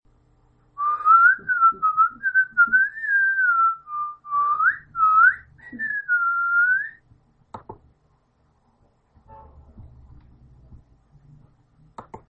Ambient sound effects
Descargar EFECTO DE SONIDO DE AMBIENTE SILVAR SILVAR - Tono móvil